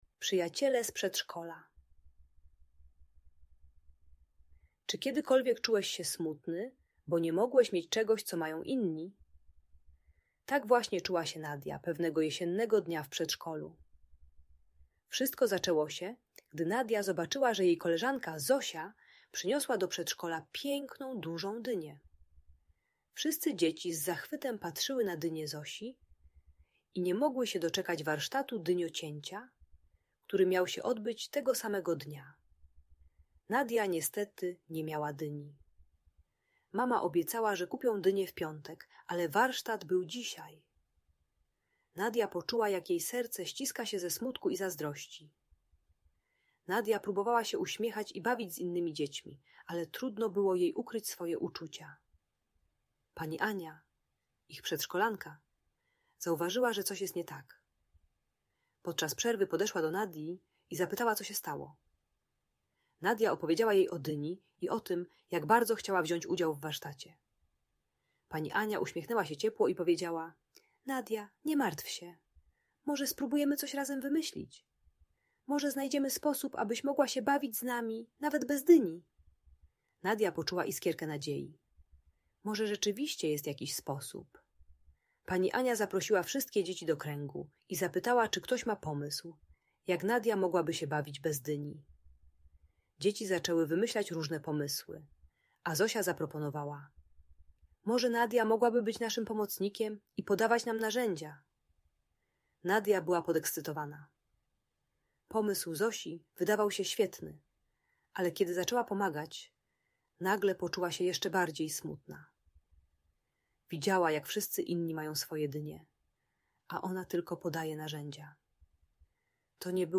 Przyjaciele z Przedszkola - Niepokojące zachowania | Audiobajka